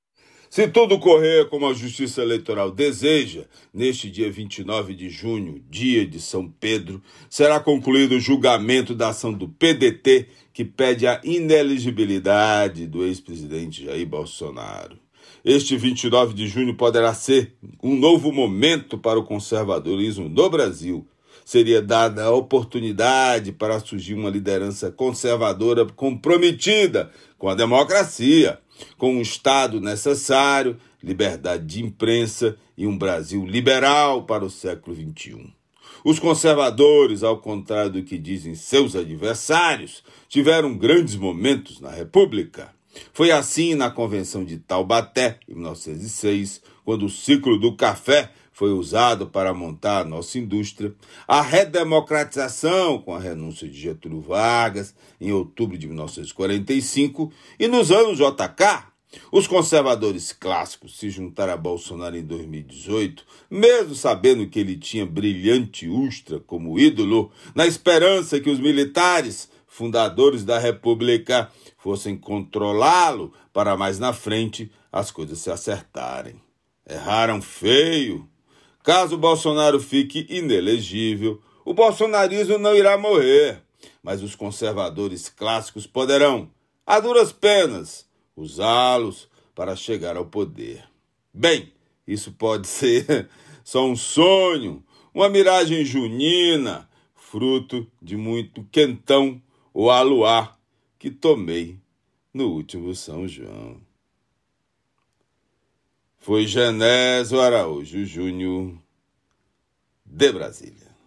Comentário desta terça-feira (27/06/23) do jornalista